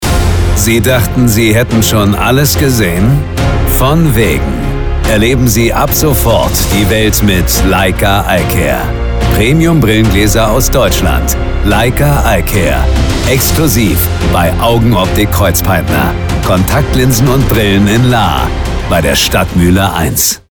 Dietmar Wunder ist unter anderem der deutsche Synchronsprecher
Hier gerne mal ein Hörbeispiel von Dietmar Wunder.
Augenoptik-Kreuzpeintner-Von-wegen-20s.mp3